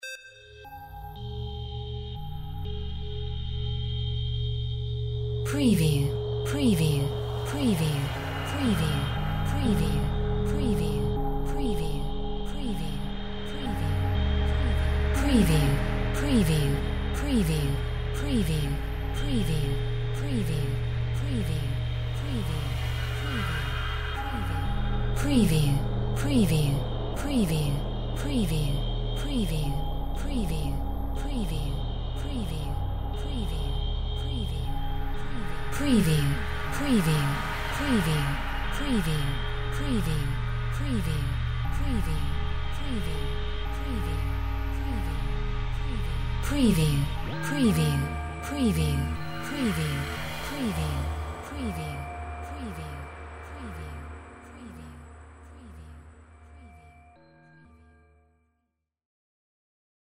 Drone Matrix Random FX 02
Warm bell-like drone with industrial overtones and sweeps.
Stereo sound effect - Wav.16 bit/44.1 KHz and Mp3 128 Kbps
Tags: pad
previewDRONE_MATRIX_RANDOM_FX_WBSD02.mp3